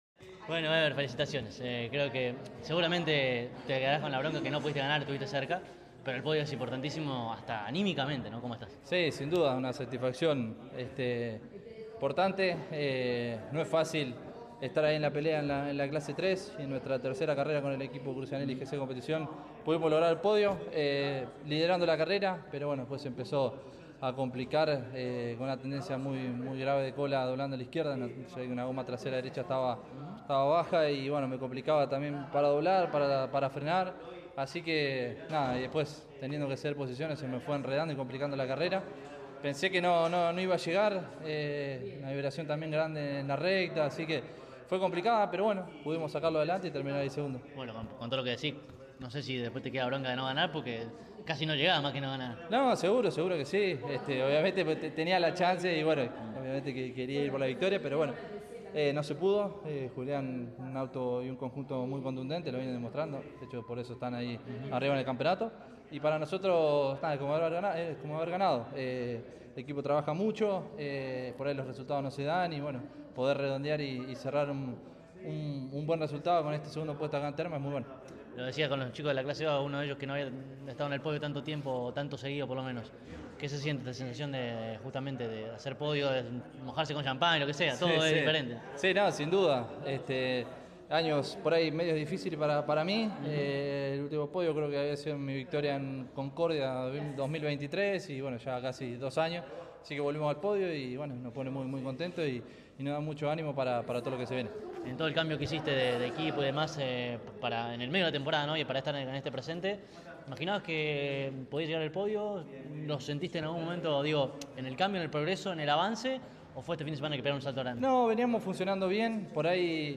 Los más encumbrados de la séptima final del año de la Clase 3 del TN, disputada en la provincia de Santiago del Estero, pasaron por los micrófonos de CÓRDOBA COMPETICIÓN.